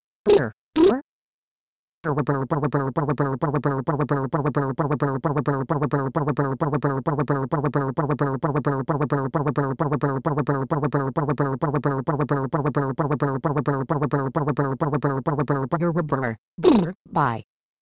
The 2013 version of the US English Siri female voice, originally named Nicky, used in the add-on has interesting behaviors with the string "brr," from odd sounds to an interesting beat if "brr" is typed multiple times.